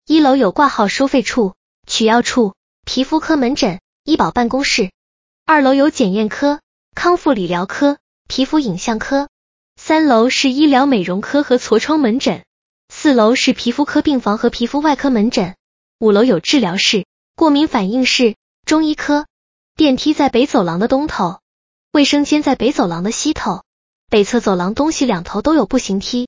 楼层科室分布语音播报